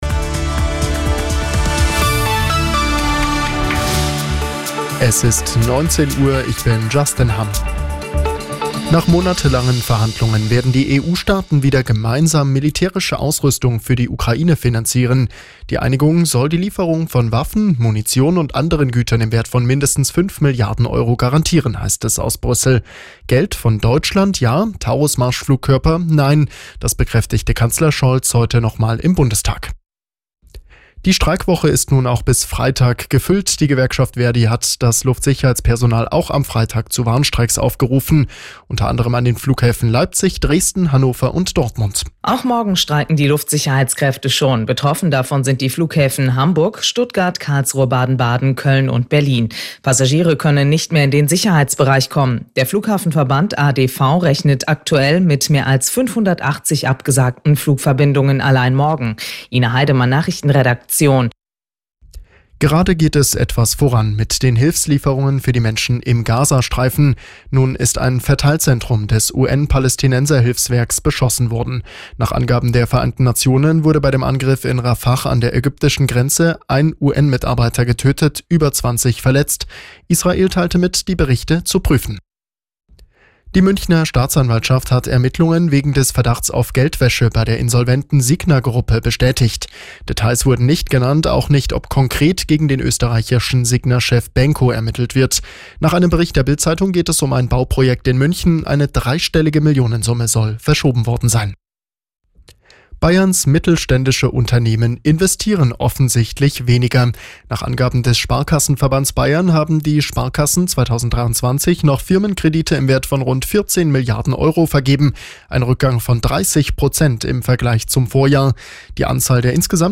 Die aktuellen Nachrichten von Radio Arabella - 13.03.2024